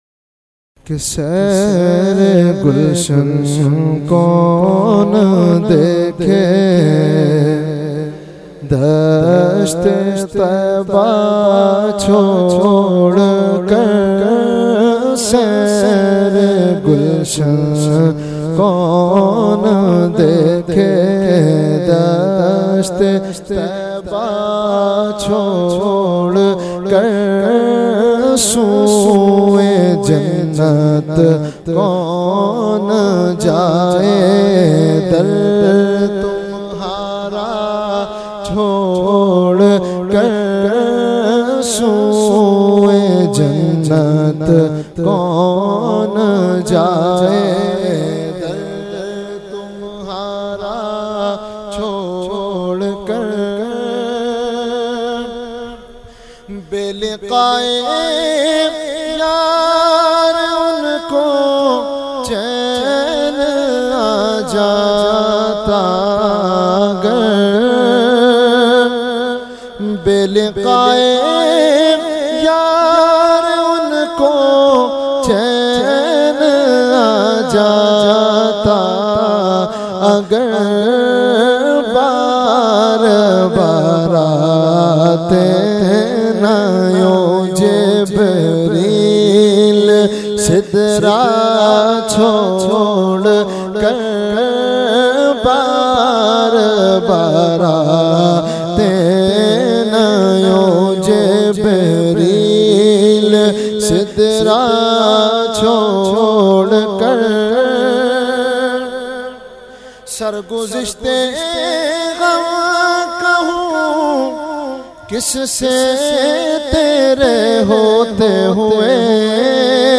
Ikhtitaam e Dua e Hizbul Bahar Mehfil held on 30 April 2022 at Dargah Alia Ashrafia Ashrafabad Firdous Colony Gulbahar Karachi.
Category : Naat | Language : UrduEvent : Khatam Hizbul Bahr 2024